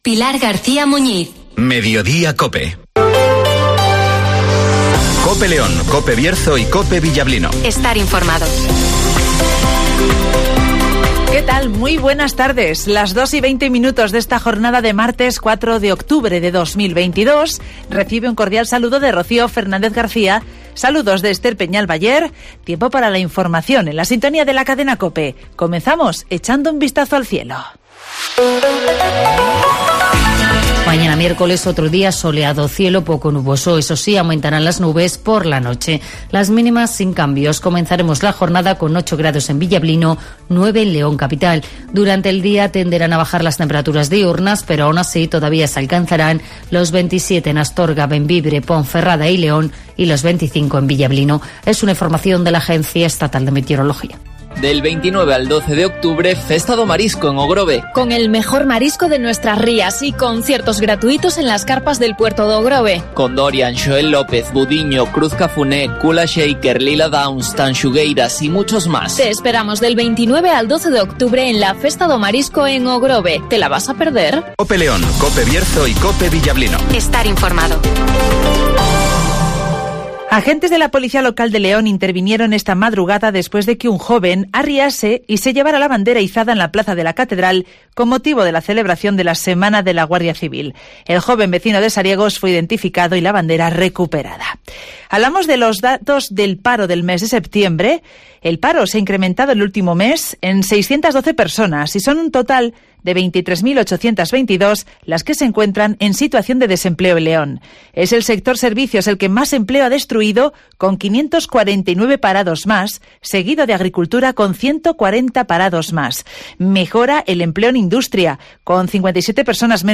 Escucha aquí las noticias con las voces de los protagonistas.